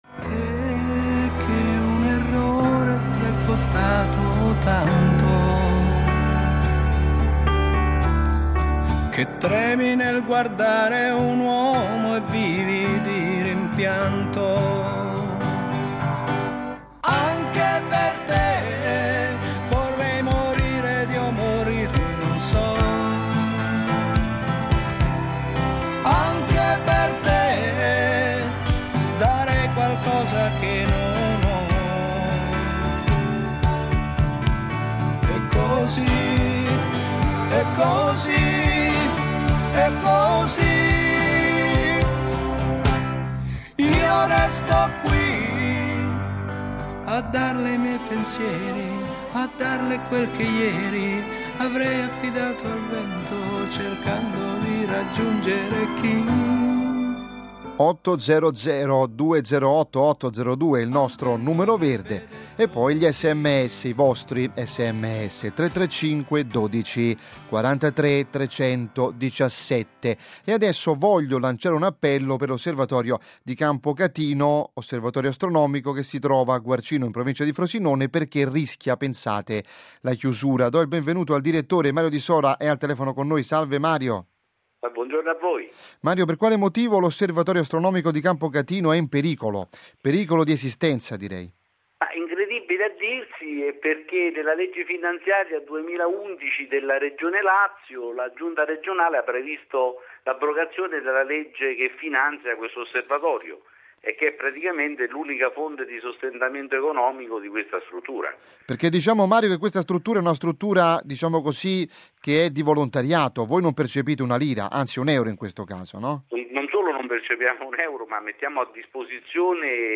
Scarica il file dell’intervista, cliccando qui (formato .mp3 – dimensione 8.18 MB – durata 8:55) o ascolta l’intervista con il seguente player: